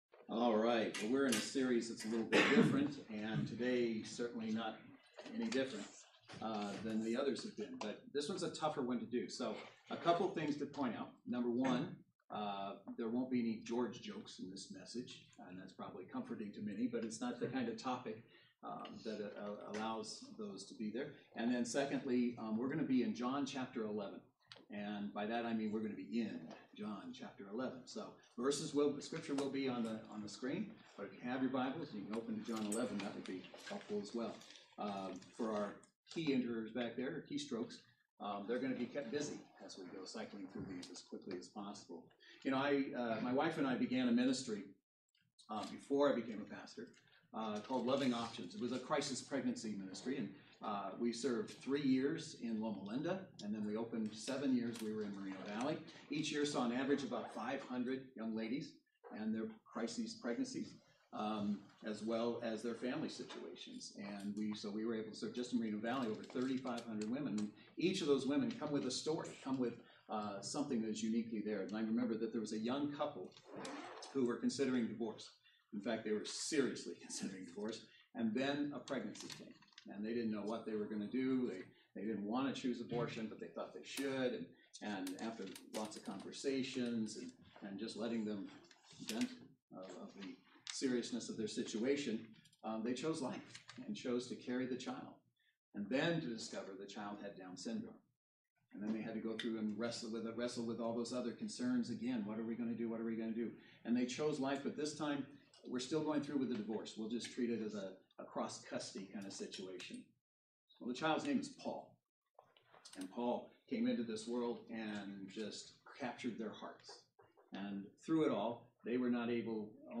John 11 Service Type: Saturday Worship Service Bible Text